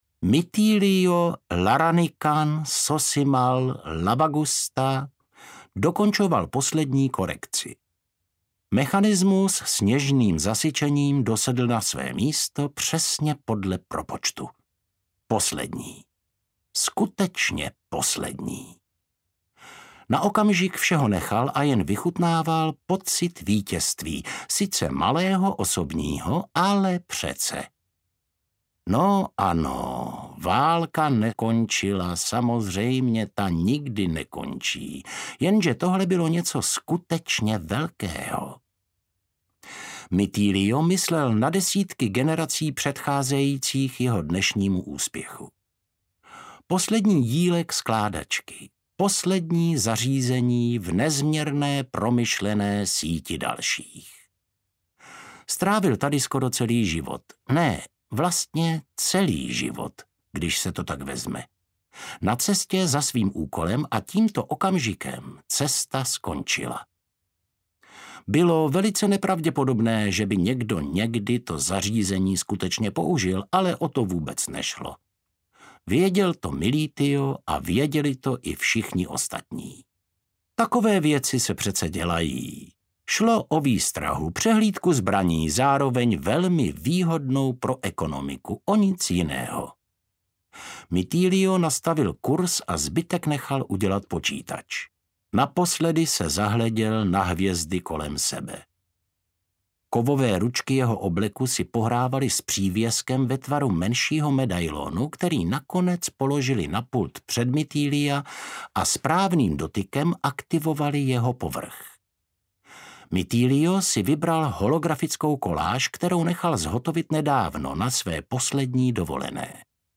Světelné (k)roky audiokniha
Ukázka z knihy